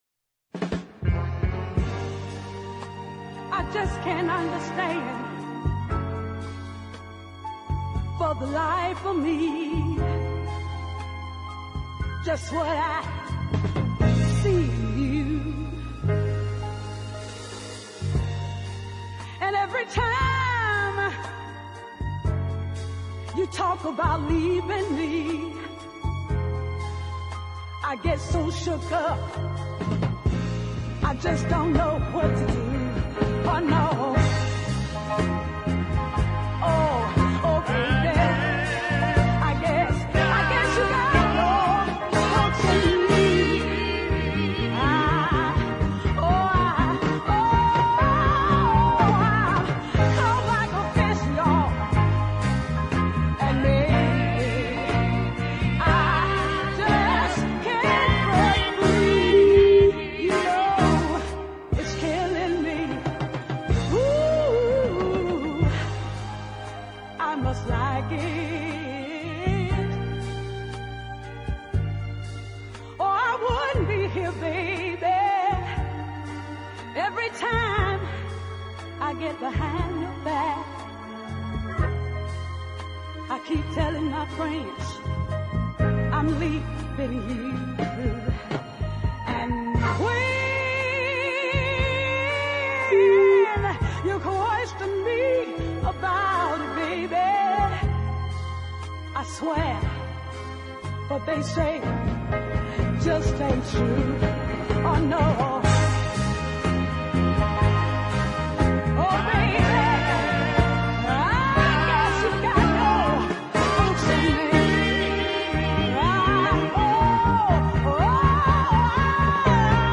excellent ballad
wailing in front of some super gospel harmonies